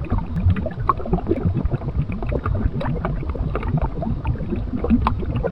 lava.ogg